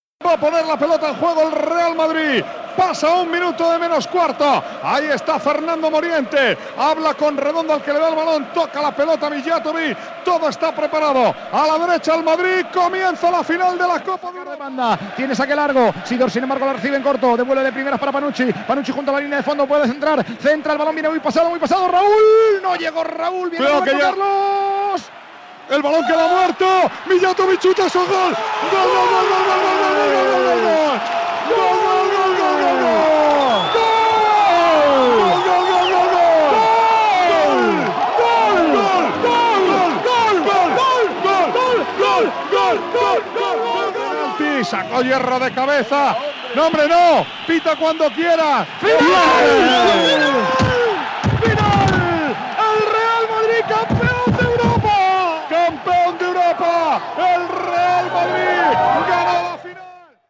Narració d'un gol del Real Madrid a la final de la Copa d'Europa de futbol masculí. I narració dels segons finals del partit
Esportiu